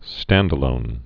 (stăndə-lōn)